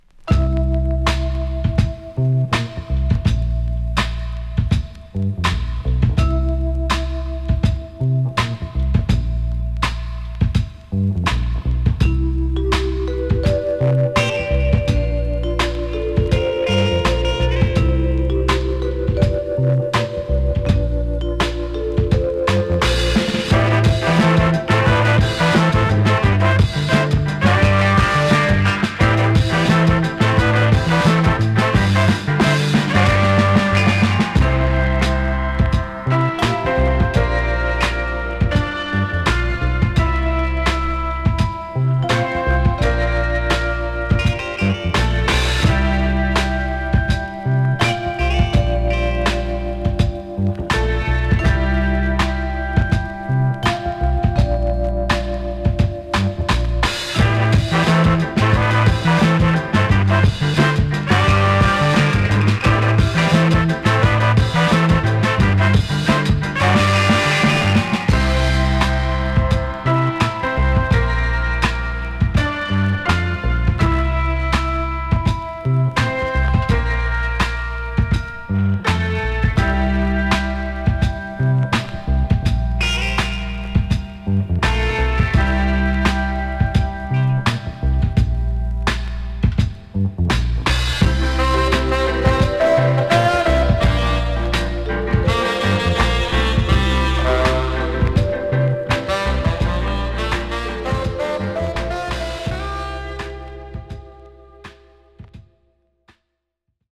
インスト・ファンク傑作です!!